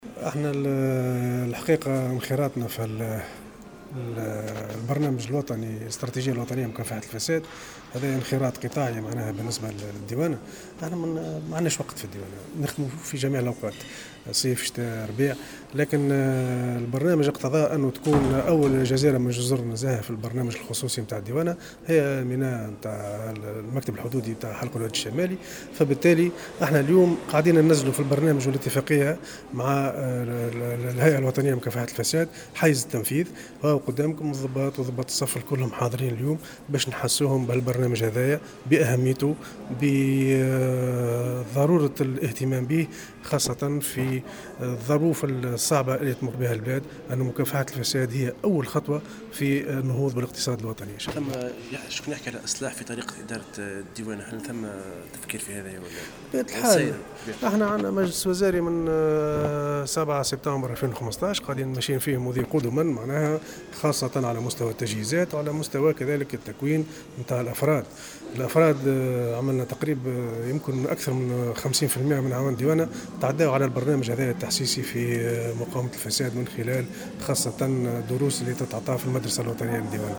وبيّن بن حسن، في تصريح لمراسل الجوهرة أف أم، على هامش يوم تحسيسي لفائدة أعوان مكتب الديوانة النموذجي بحلق الوادي الشمالي حول الفساد انعقد اليوم السبت، أن مكافحة الفساد تعتبر من أولويات السلك الديواني في ظل الظروف الصعبة التي تمر بها البلاد، بما يقتضيه ذلك من تعزيز التجهيزات وتكوين الأعوان من خلال تقديم دروس في هذا الإطار انتفع منها نحو 50 % من الأعوان في المدرسة الوطنية للديوانة.